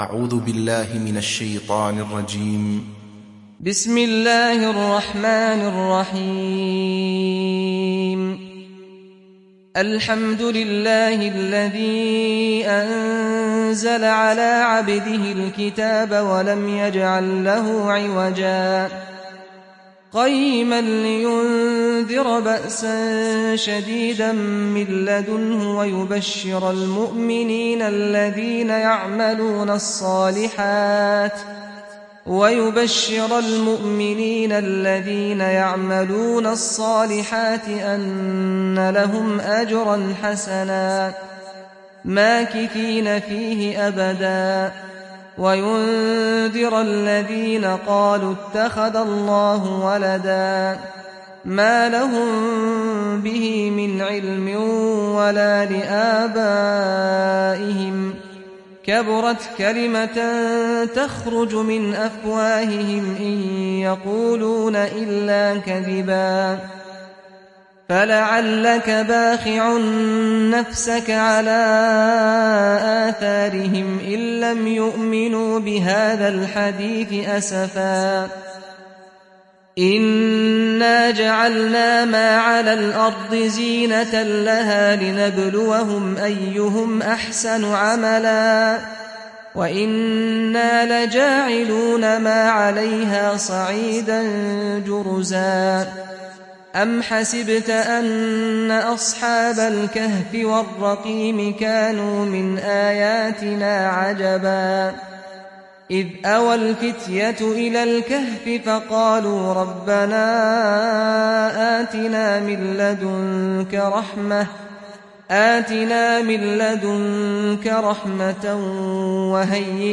تحميل سورة الكهف mp3 بصوت سعد الغامدي برواية حفص عن عاصم, تحميل استماع القرآن الكريم على الجوال mp3 كاملا بروابط مباشرة وسريعة